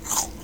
eat-bite1.wav